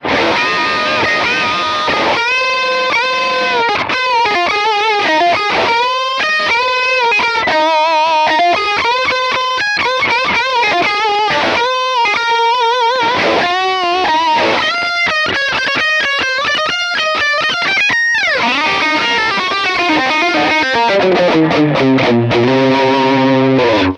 Guitar: Brian May Red Special